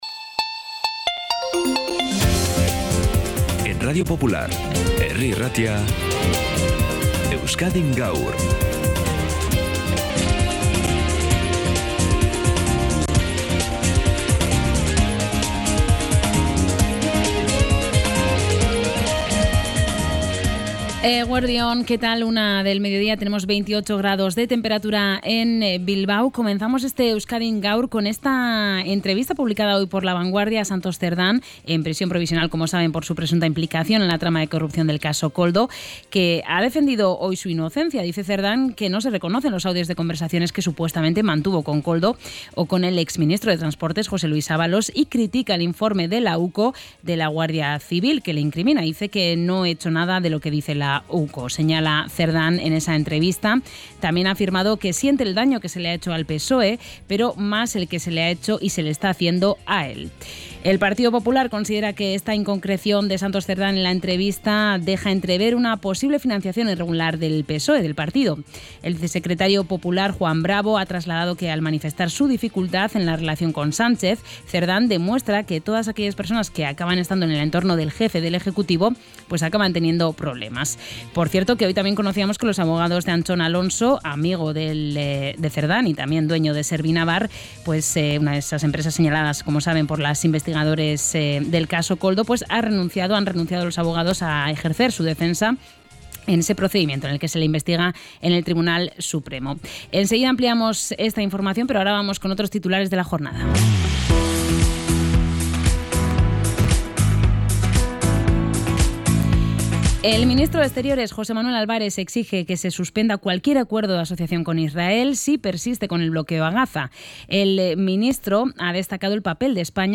Los servicios informativos de Radio Popular-Herri Irratia te mantienen al día con toda la actualidad de Bizkaia y del mundo